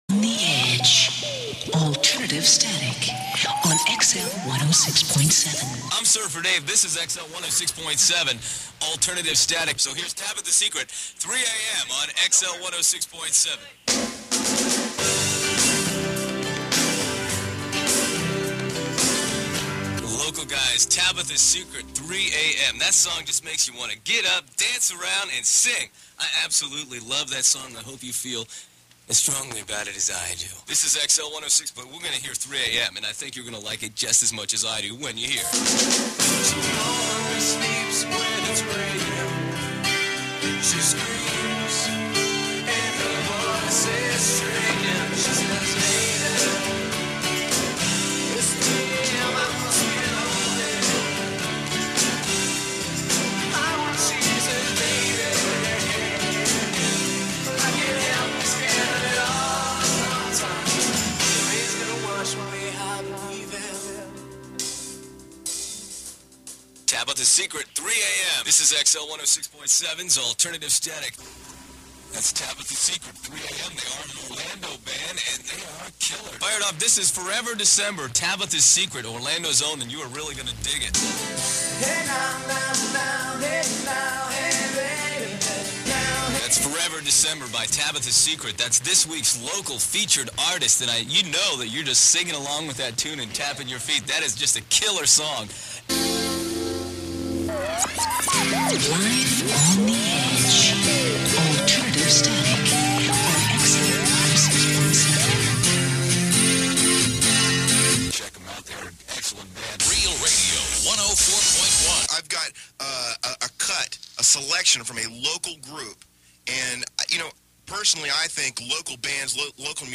Radio Montage - Local Orlando Stations